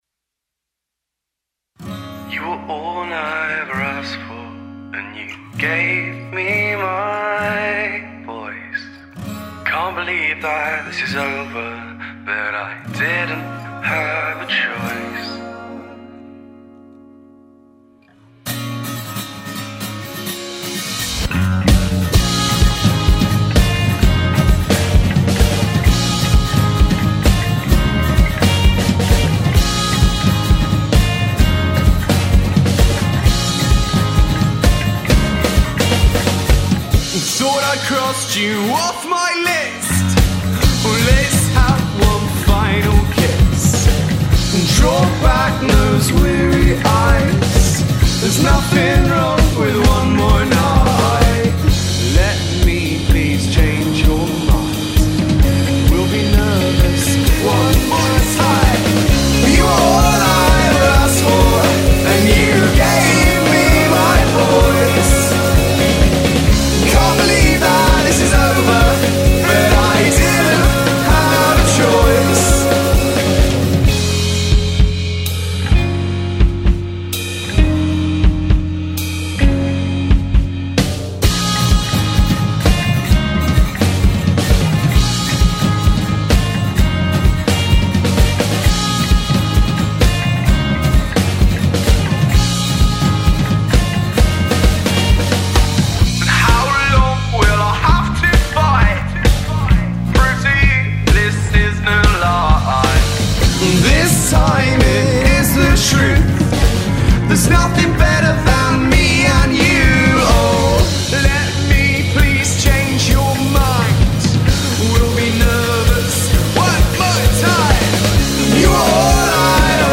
Derby/Leeds, UK singer-songwriter